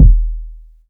Kicks
KICK.24.NEPT.wav